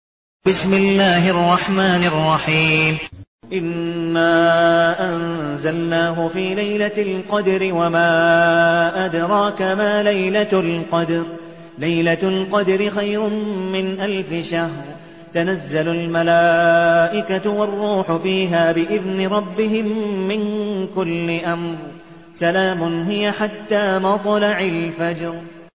المكان: المسجد النبوي الشيخ: عبدالودود بن مقبول حنيف عبدالودود بن مقبول حنيف القدر